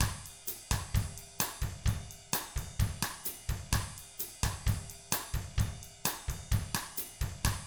129BOSSAT3-R.wav